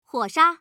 noname / audio / card / female / sha_fire.mp3